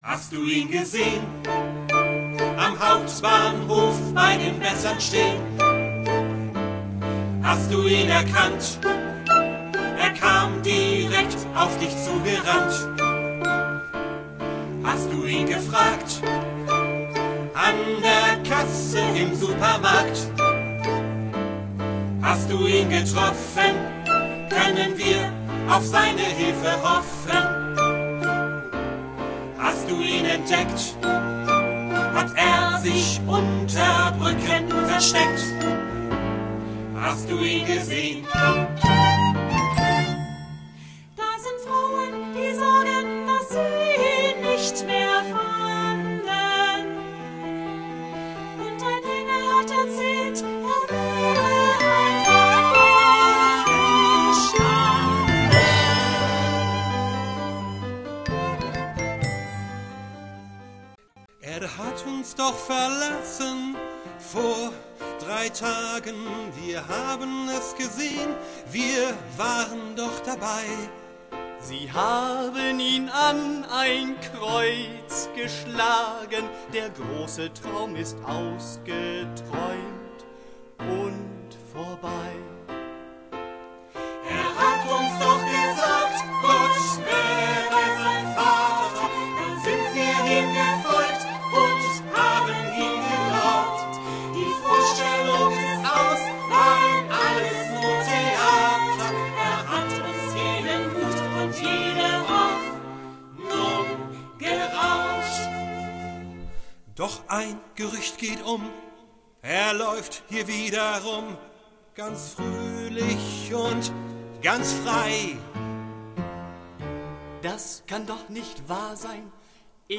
Biblisches Singspiel für Chor, Solisten und Instrumente